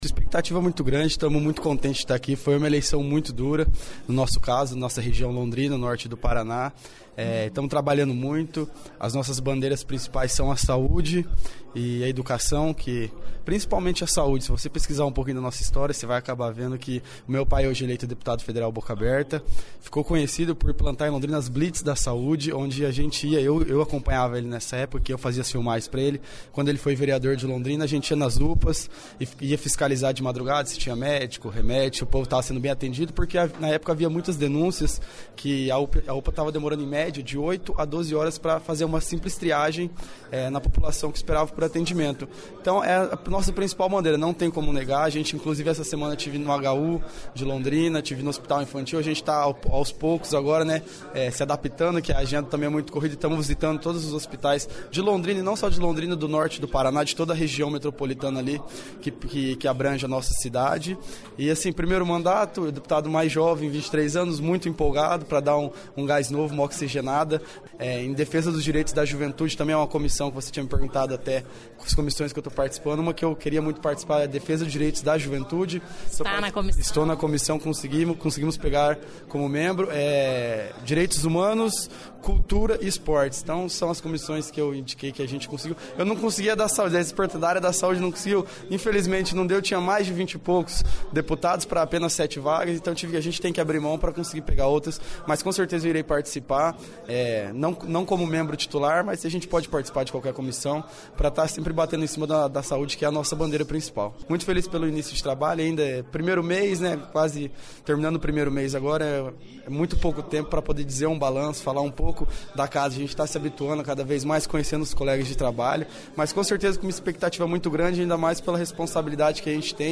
Ouça entrevista com este jovem deputado do Norte do Paraná, que chega pela  primeira vez a um cargo público.